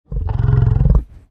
Звуки бизона
Бизон Альтернативный